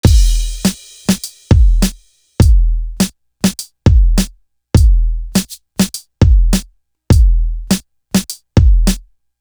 Air It Out Drum.wav